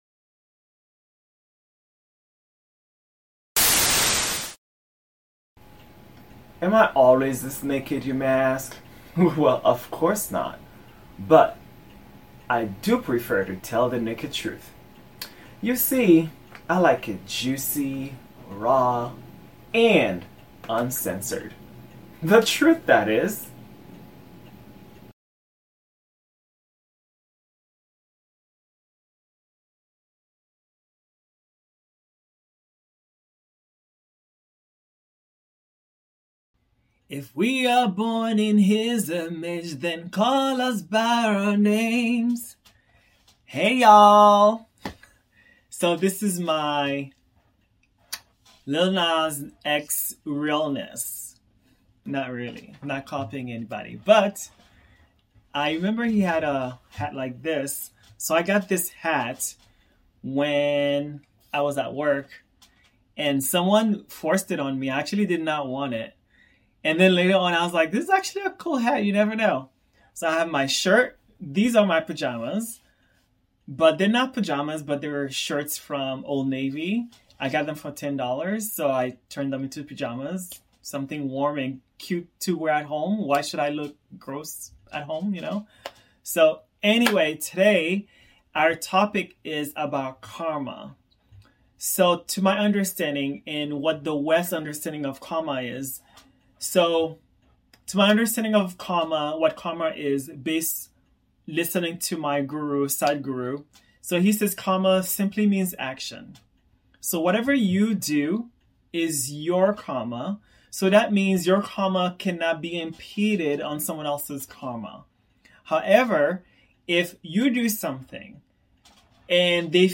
PodCast. Solo.